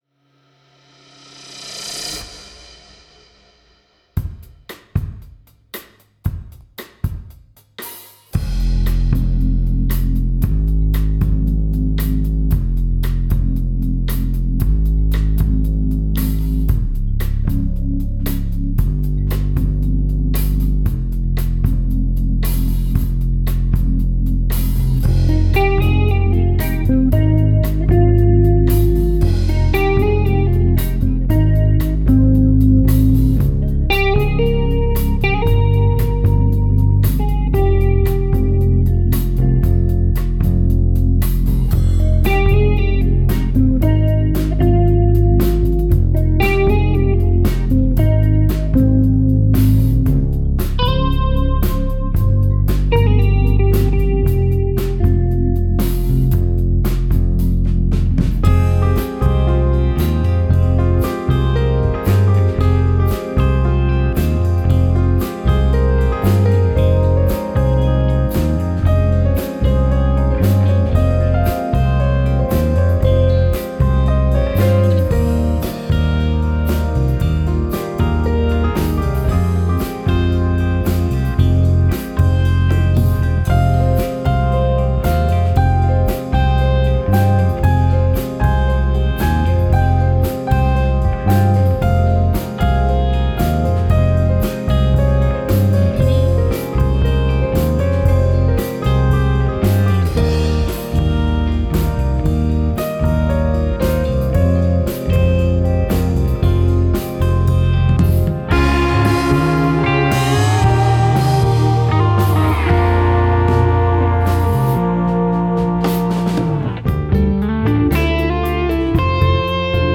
Recording keyboards remotely